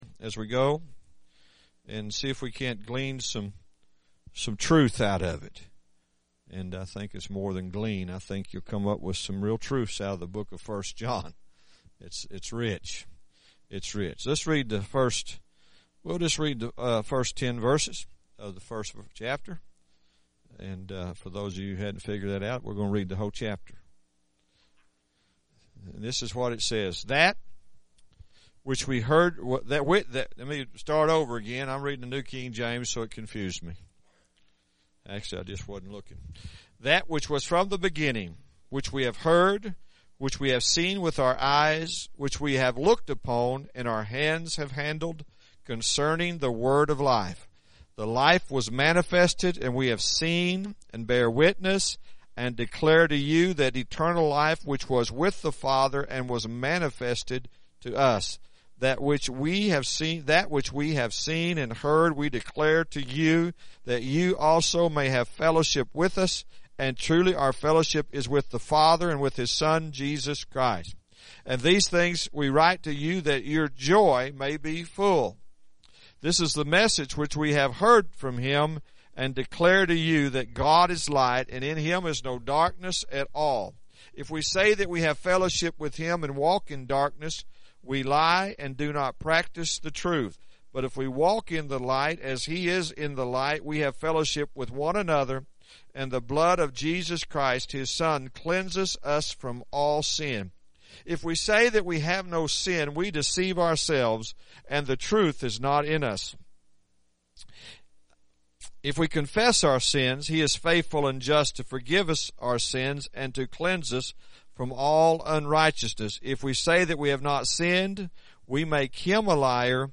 1 John Series – Sermon 1